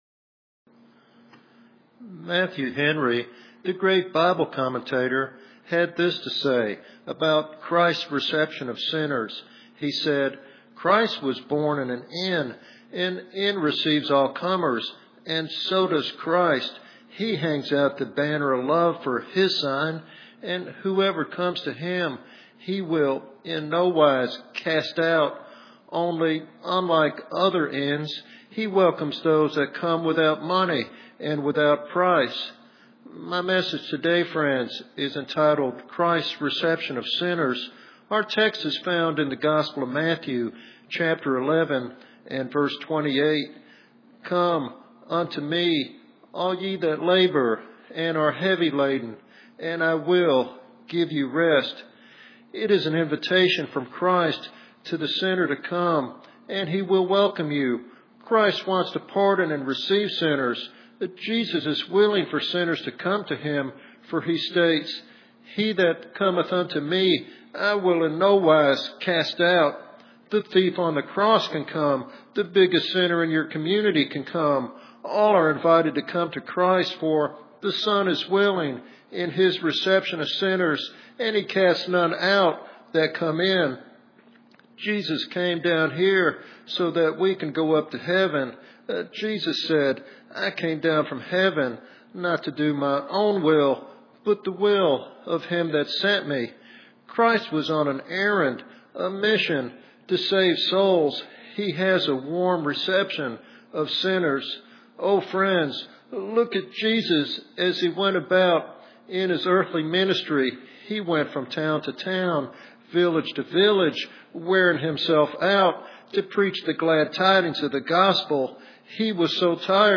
In this heartfelt evangelistic sermon